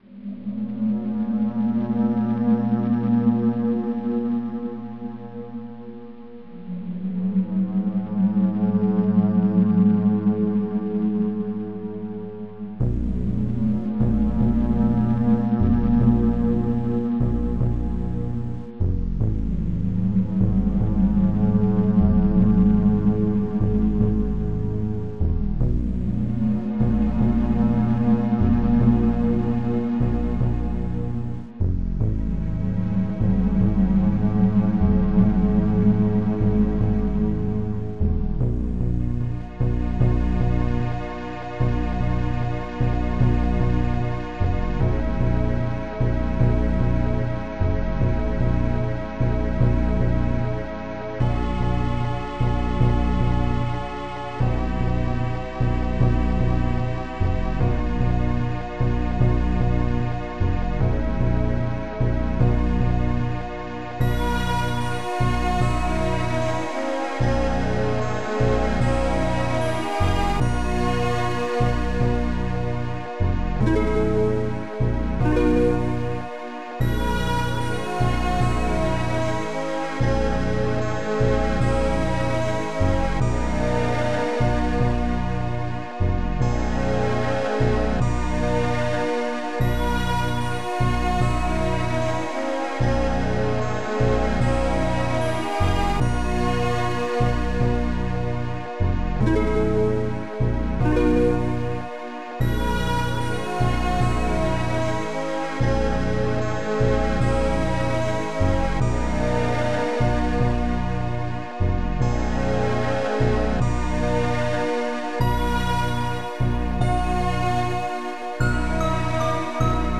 Protracker Module  |  1995-08-20  |  275KB  |  2 channels  |  44,100 sample rate  |  5 minutes, 7 seconds
Protracker and family
harp pluck 5
chord one
filtered bass
taiko
single harp
HandDrum